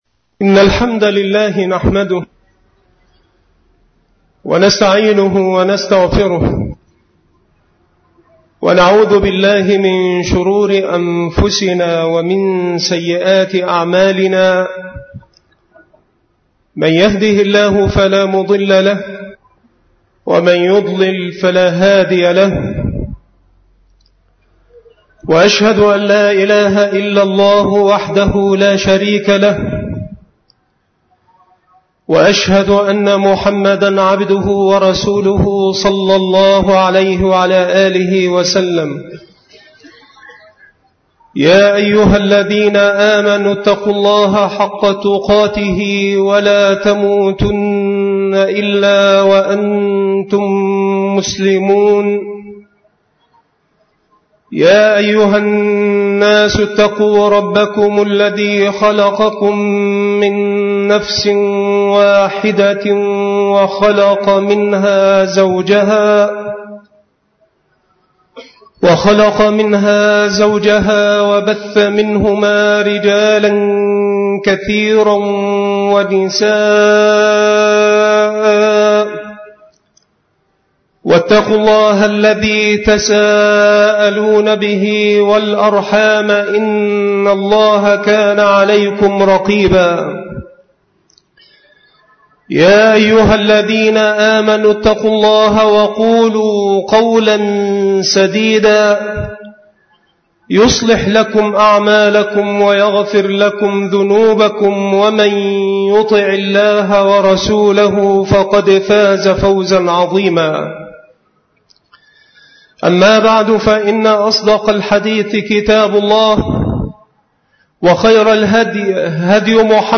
مكان إلقاء هذه المحاضرة بالمسجد الشرقي - سبك الأحد - أشمون - محافظة المنوفية